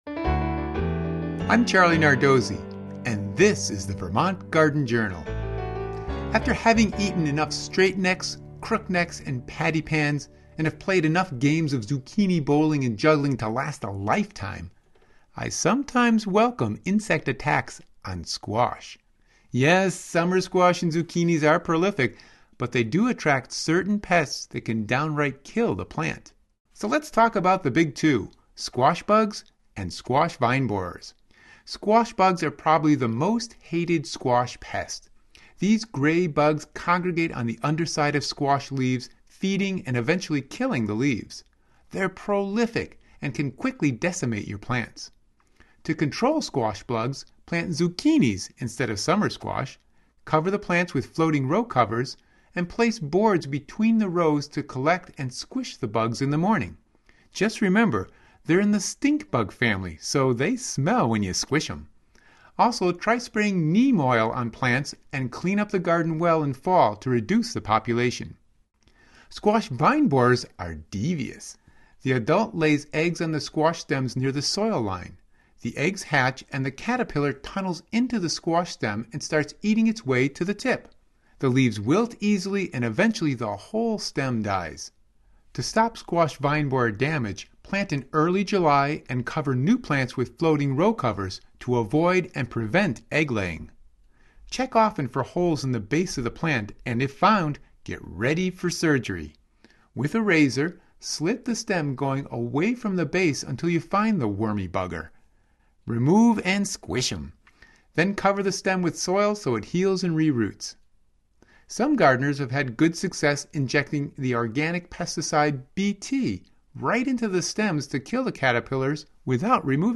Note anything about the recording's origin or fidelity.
From The Vermont Garden Journal on Vermont Public Radio.